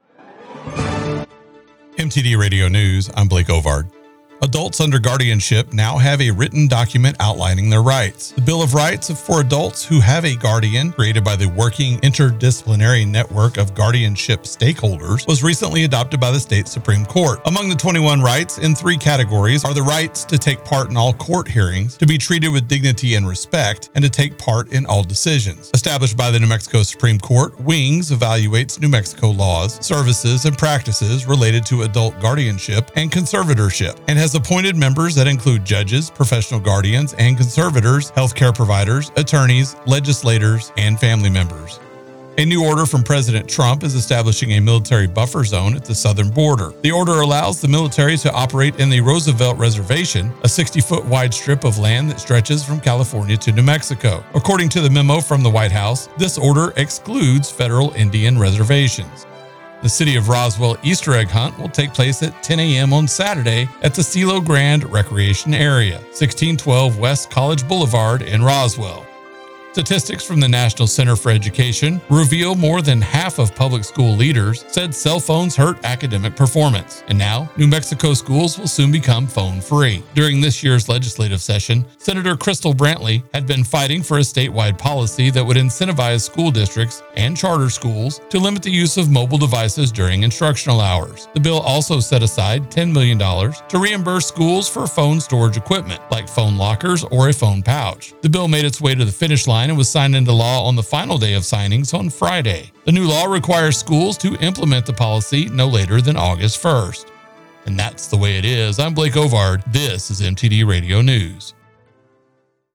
W105 News – New Mexico and West Texas